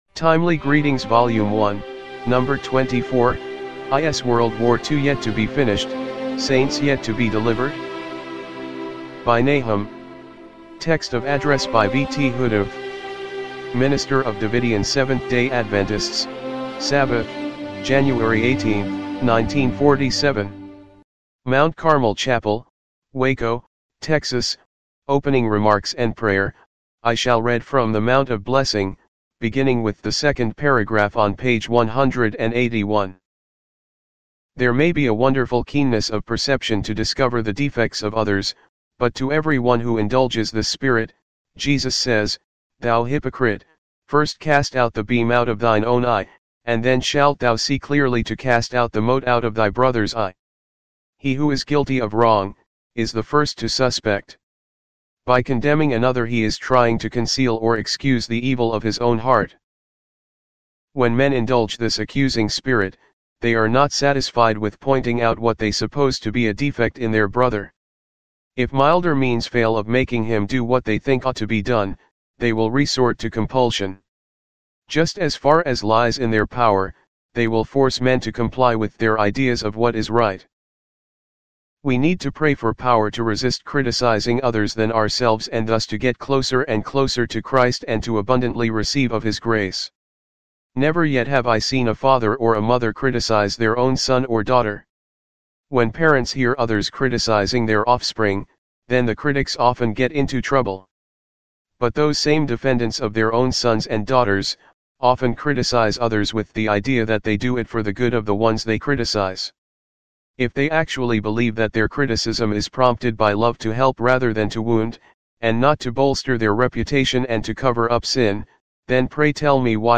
timely-greetings-volume-1-no.-24-mono-mp3.mp3